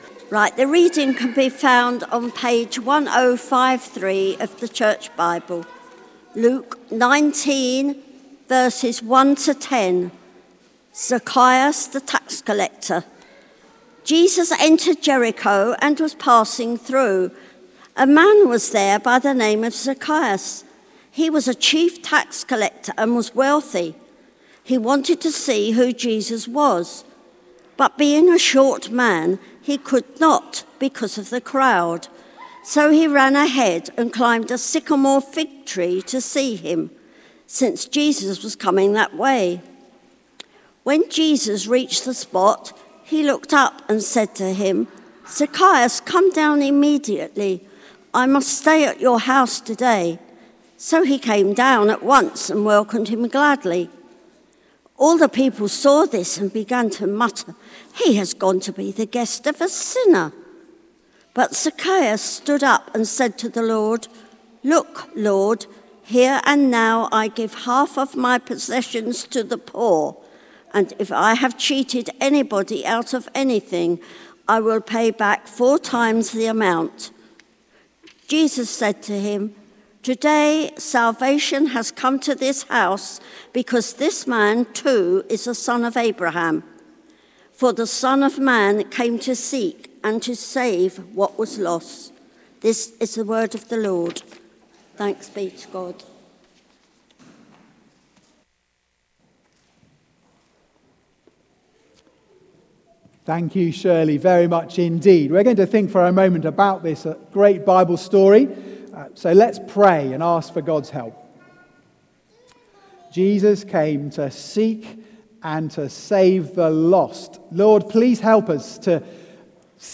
Hotshots Summer Club 2025 Sermon